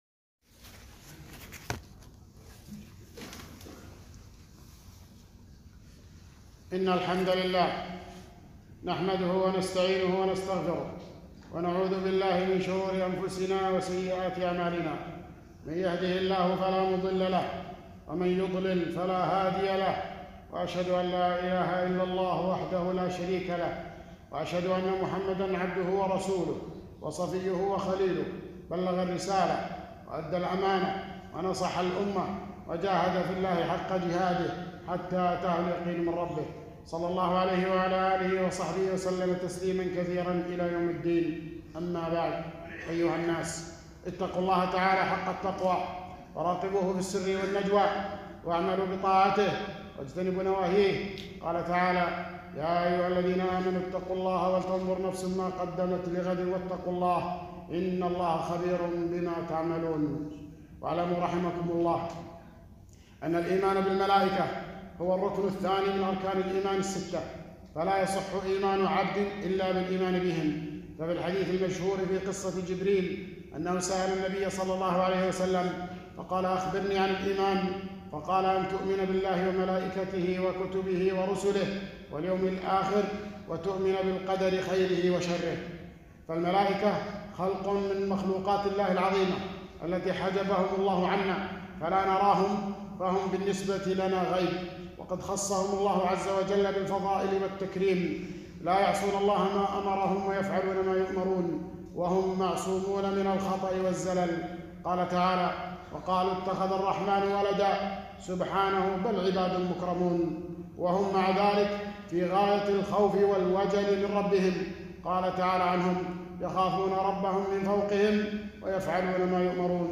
2- خطبة - الإيمان بالملائكة